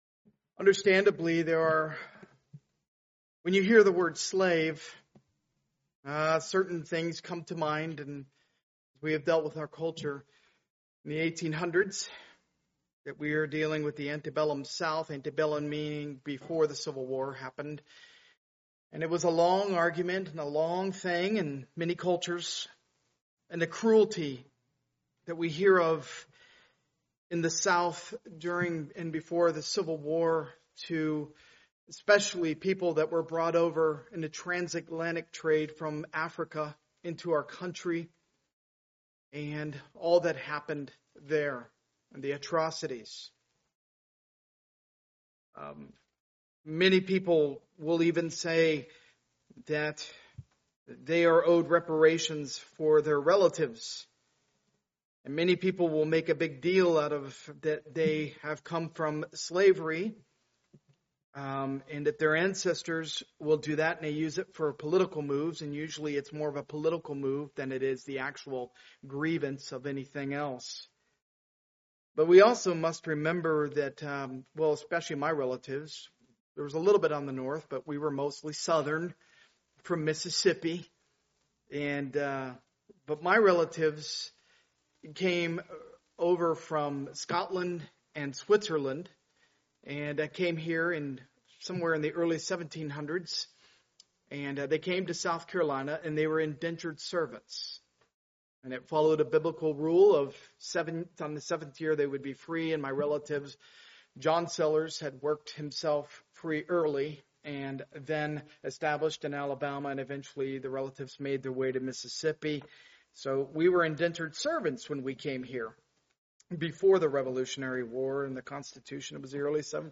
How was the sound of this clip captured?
Part of the The Book of Exodus series, preached at a Morning Service service.